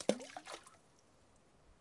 溅射
我在池塘边设置了录音机，当时天刚开始黑，然后在路上走了走。15分钟后我回来，决定捡起一块石头扔进去。这是它溅起的水花。
标签： 池塘 岩石 飞溅
声道立体声